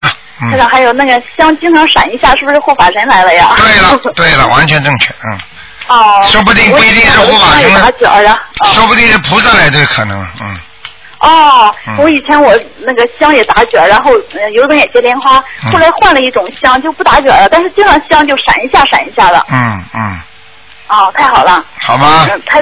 目录：剪辑电台节目录音_集锦